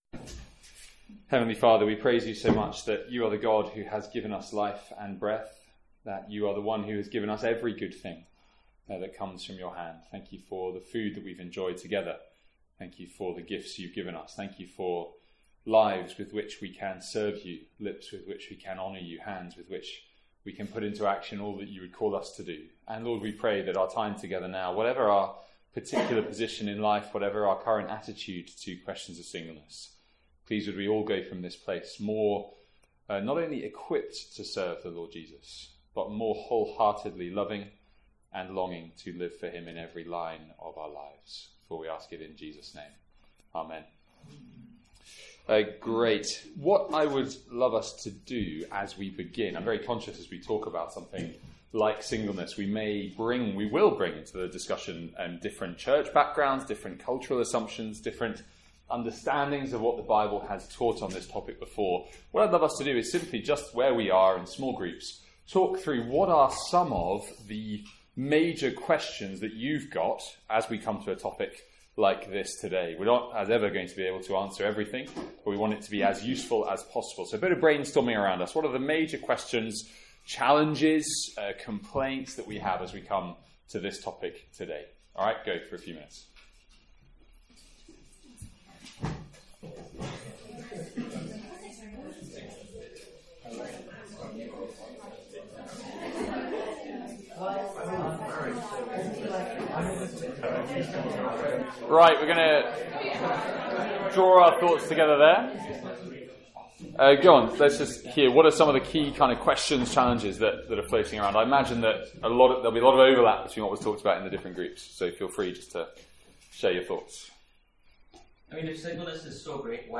From our final student lunch of the academic year.
WARNING: from 24 minutes in there is audio distortion that may be painful if listening at high volume or over headphones.